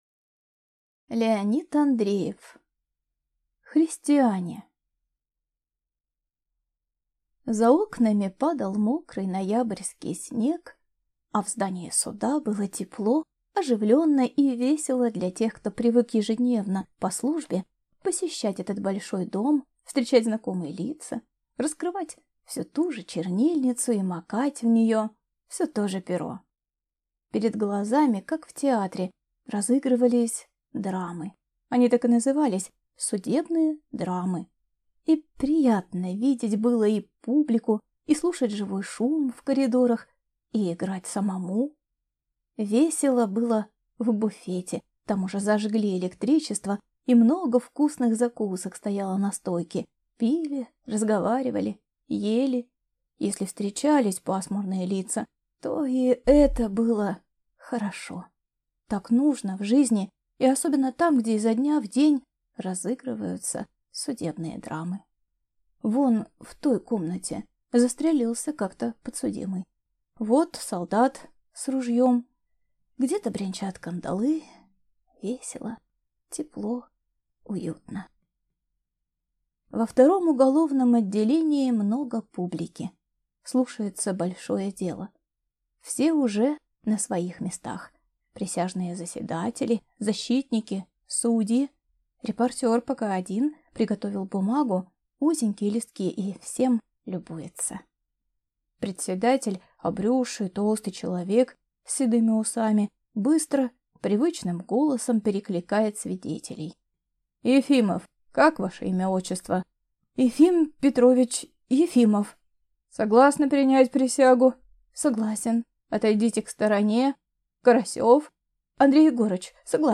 Аудиокнига Христиане | Библиотека аудиокниг